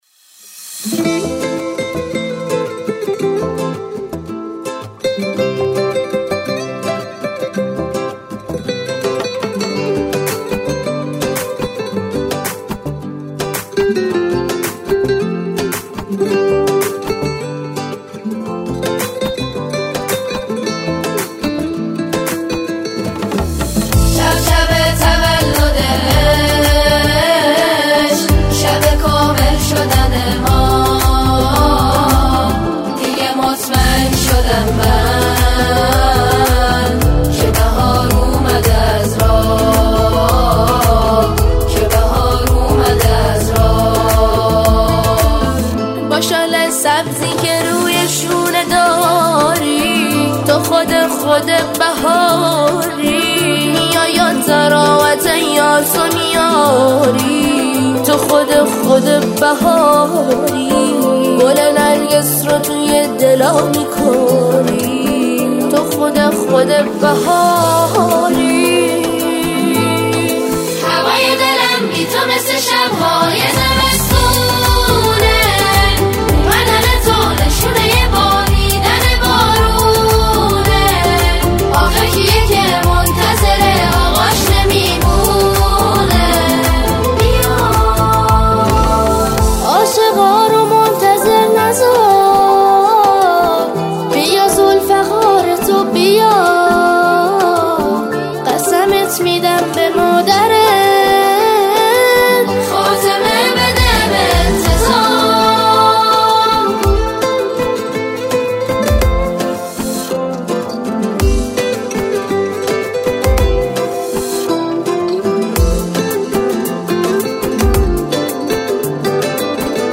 سرودهای امام زمان (عج)